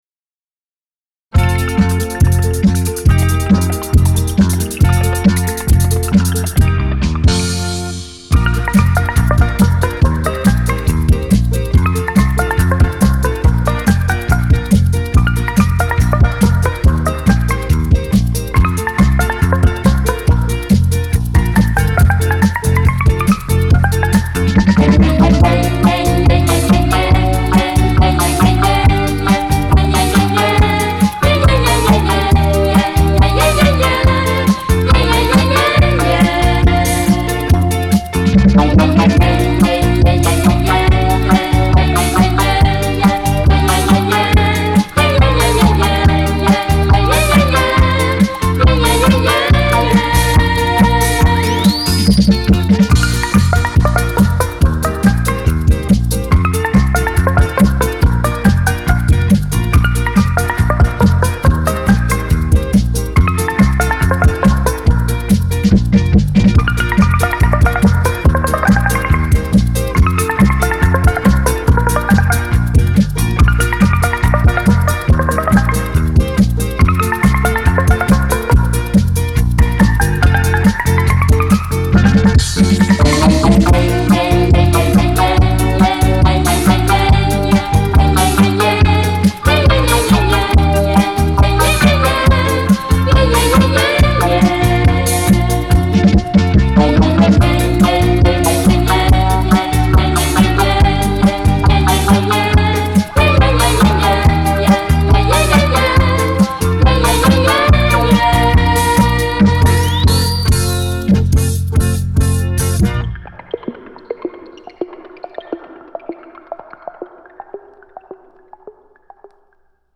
感谢版主继续分享这个系列的第5集，珍贵的老唱片，激情的纯音乐，
很优美的旋律，LP保存制作的非常好，谢谢大大的分享！